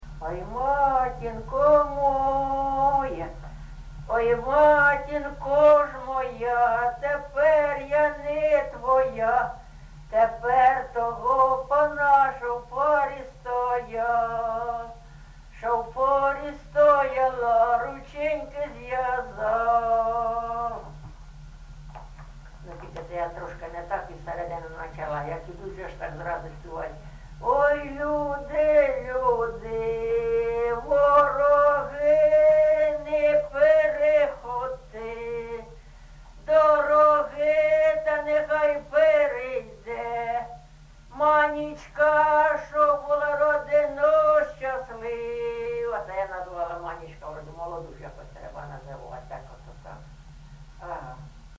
ЖанрВесільні
Місце записус. Софіївка, Краматорський район, Донецька обл., Україна, Слобожанщина